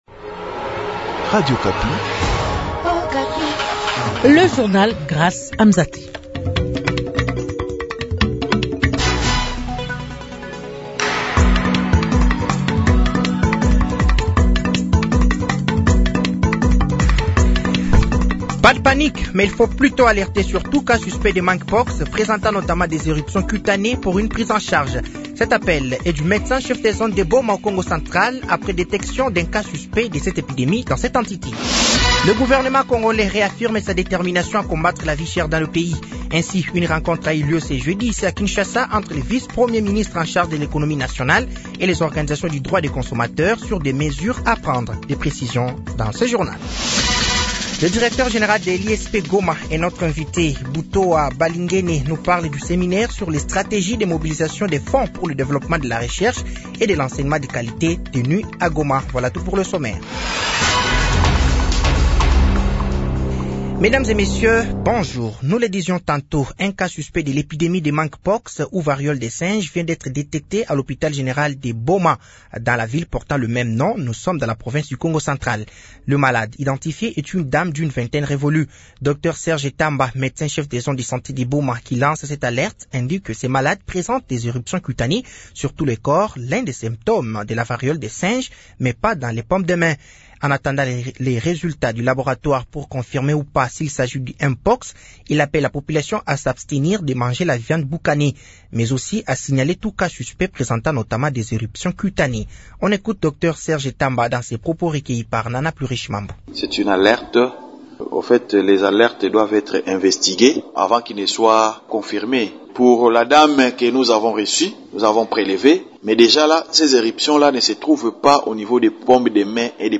Journal de 15h